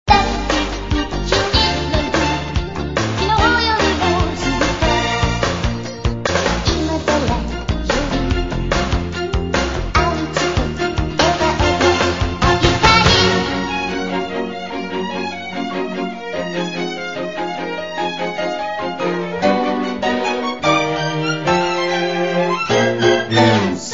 Sounds like a sped up version